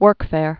(wûrkfâr)